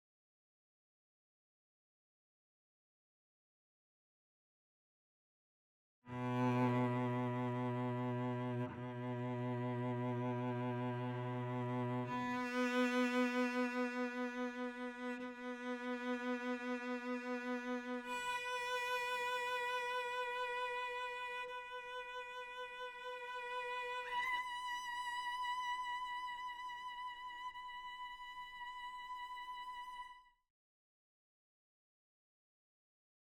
This is the Solo Cello Leg Slur.ewi with just each note held for 4 measures (at 120bpm).  The notes are B0 (silent), B1, B2, B3, B4, B5( silent).
That first B1 note has a major hiccup in it, sounds like a chair and some bow hitting something.  And B2 has a noticeable glitch in about the same spot.
badcello.wav